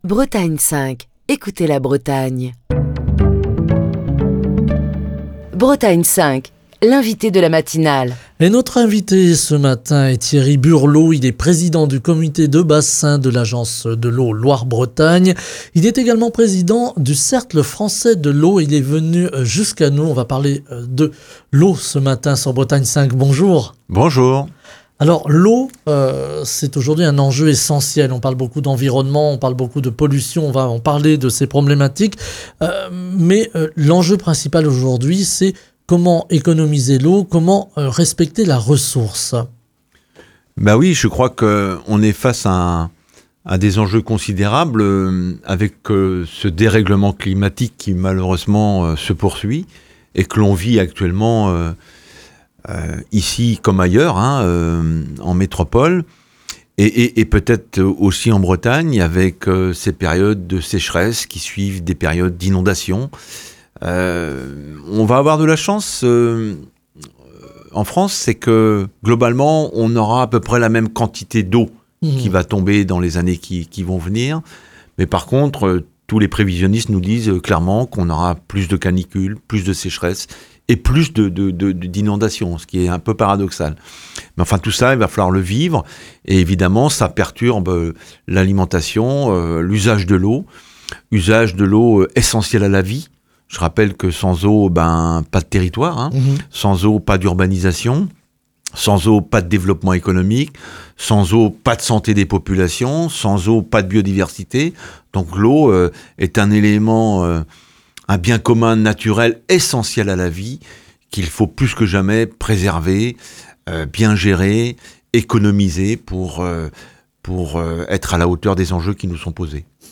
Invité de Bretagne 5 Matin ce vendredi, Thierry Burlot, président du comité de bassin de l'Agence de l’eau Loire-Bretagne et président du Cercle français de l’eau, est revenu sur les grands enjeux liés à la gestion de la ressource en eau, dans un contexte de changement climatique et de tensions croissantes sur les usages.